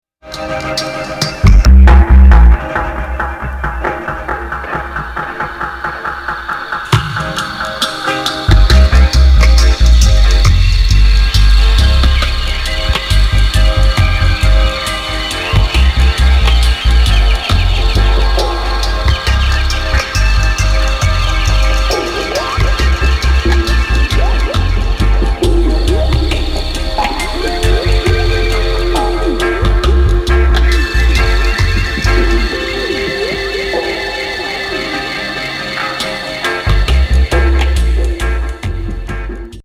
レゲー・マナーながら独特のモワモワ・ジャングル・クルージング・
トリッピン・ダブ！！